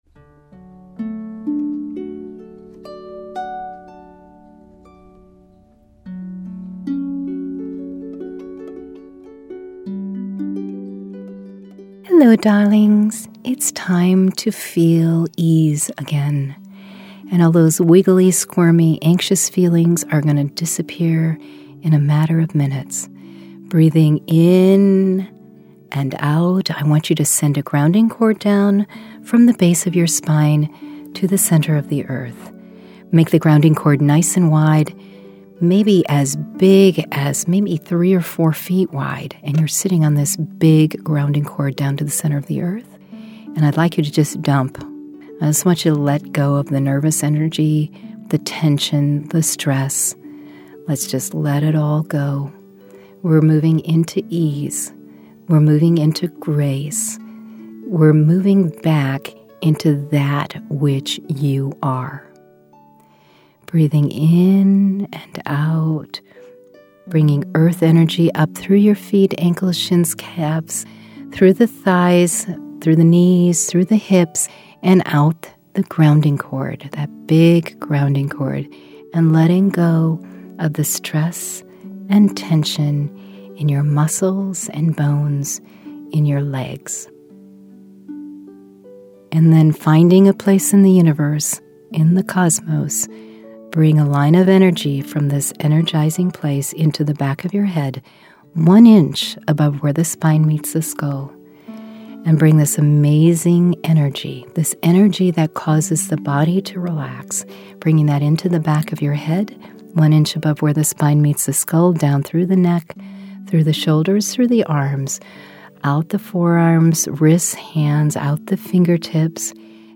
Meditations